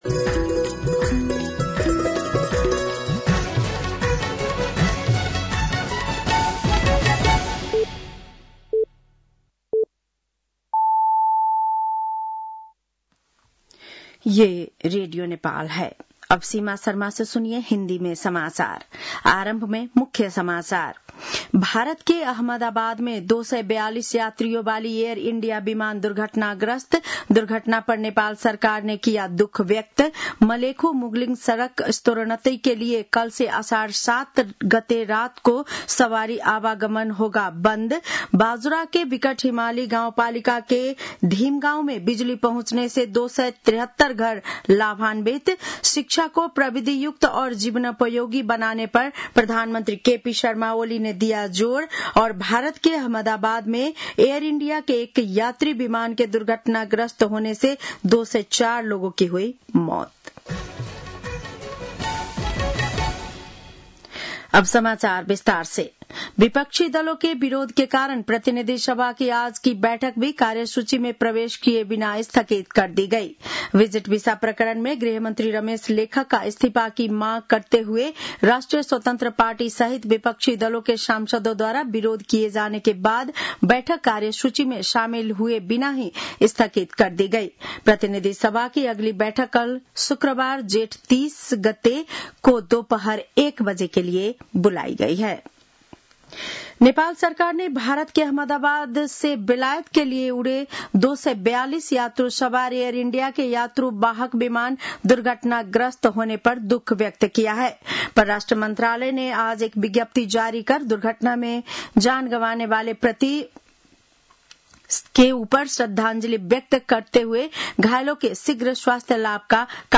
बेलुकी १० बजेको हिन्दी समाचार : २९ जेठ , २०८२